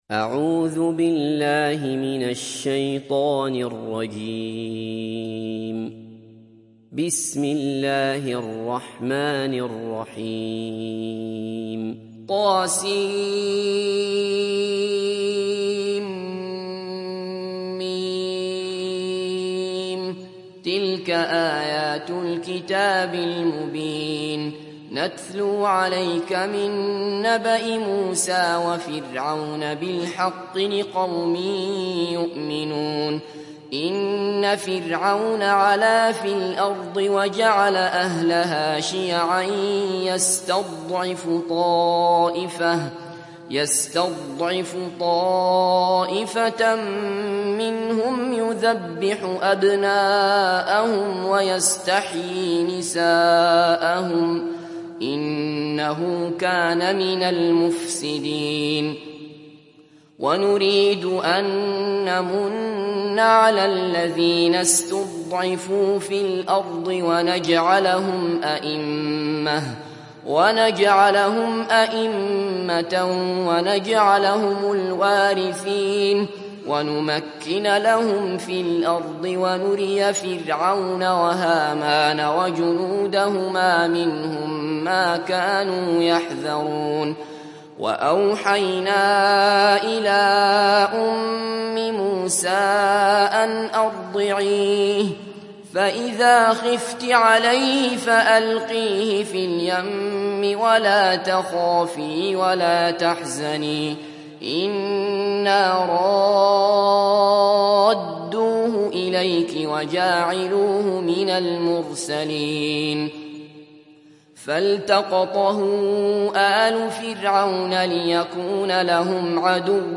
تحميل سورة القصص mp3 بصوت عبد الله بصفر برواية حفص عن عاصم, تحميل استماع القرآن الكريم على الجوال mp3 كاملا بروابط مباشرة وسريعة